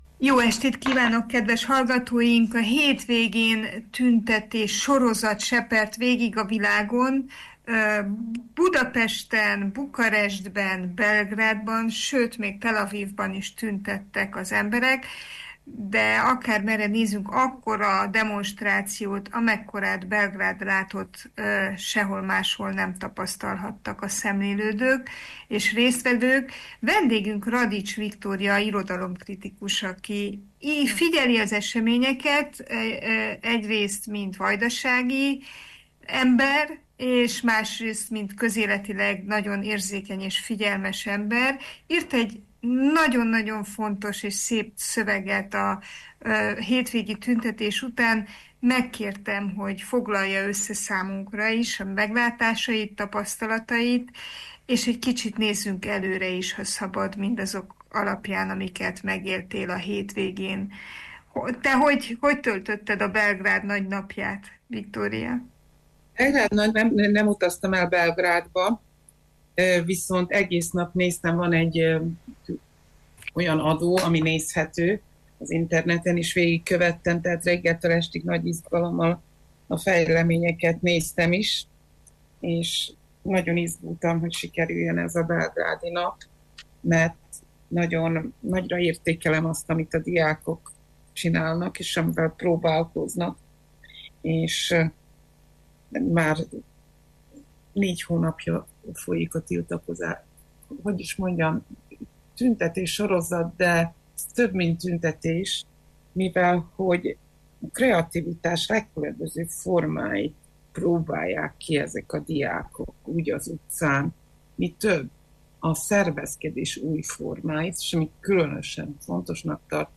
irodalomkritikussal beszélgettünk a szerbiai tiltakozások szépségéről.